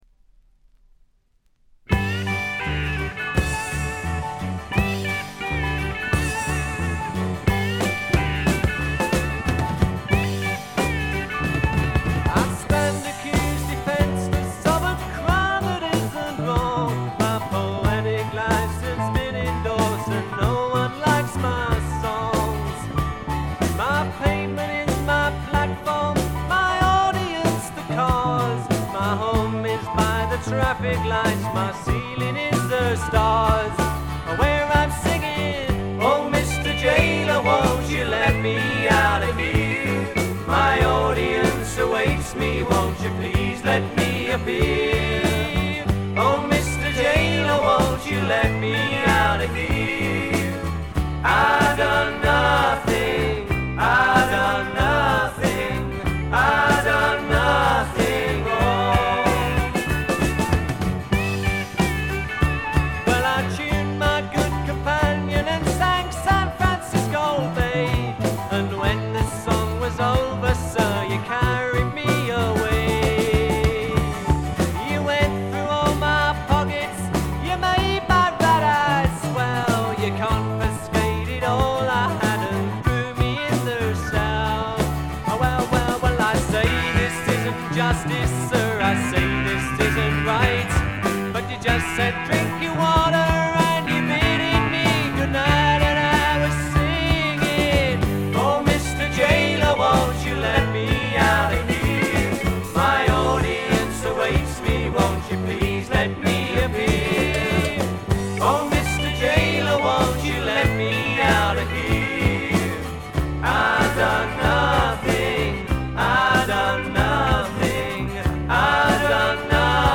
そんなわけでソロになった本作ではまさにマイルドでジェントルなフォーク／フォーク・ロック路線が満開です。
試聴曲は現品からの取り込み音源です。